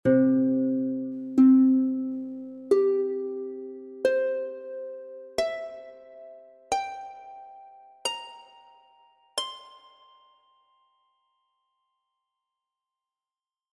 He discovered that when he divided the string exactly in half by means of the fret, the tone produced was an octave higher than the tone given out by striking the entire string; one-third of the string produced the interval of a fifth above the octave; one-fourth the length of the string produced a fourth above the fifth; one-fifth produced a third (large or major) above the fourth; one-sixth produced a third (small or minor); one-seventh produced a slightly smaller third and one-eighth produced a large second, three octaves above the sound of the entire string:
(FUNDAMENTAL TONE)